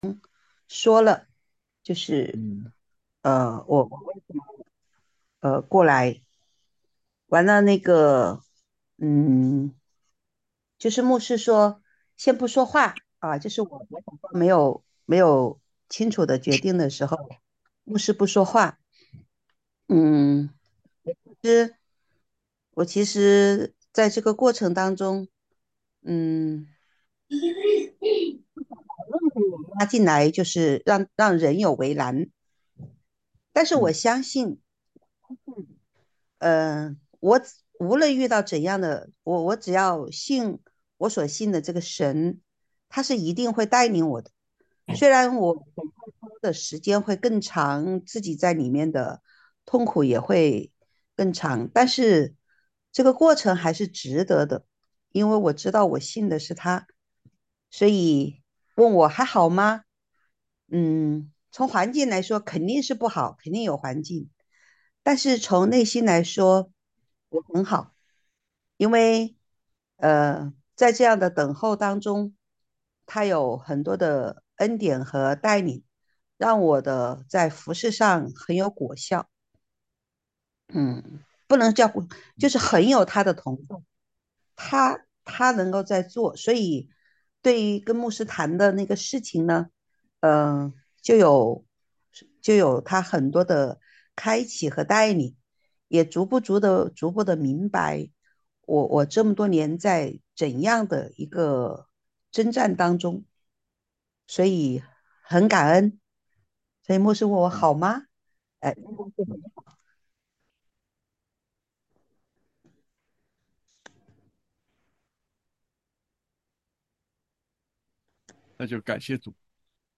主日讲道